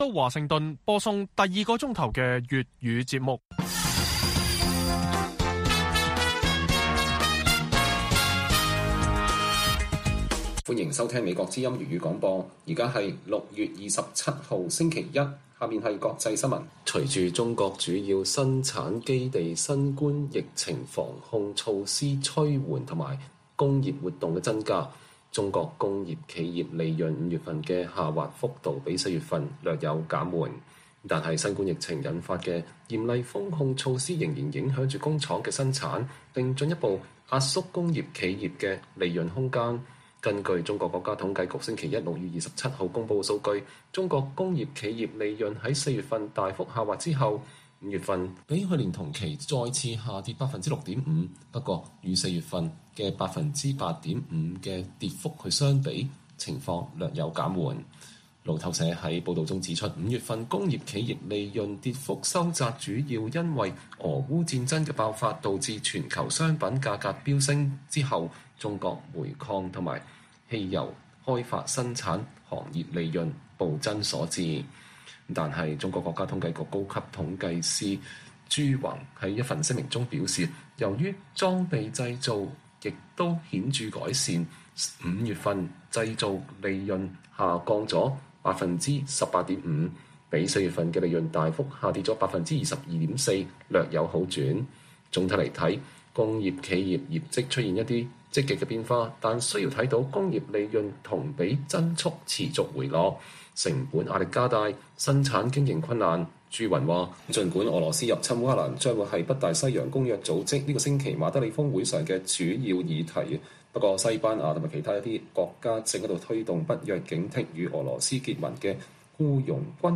粵語新聞 晚上10-11點: 日本社會活動人士積極提醒國民 香港民主自由受侵蝕與日本息息相關